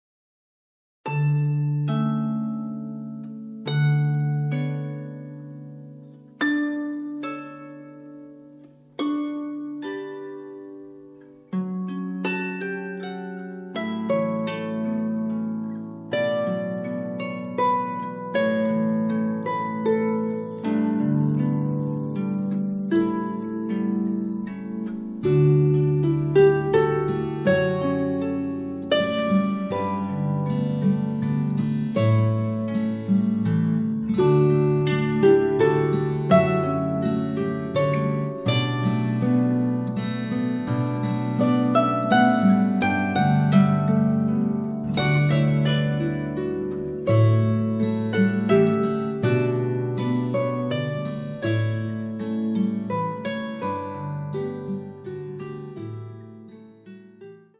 Passionate, Enchanting, Engaging, Majestic
Harp & Acoustic Guitar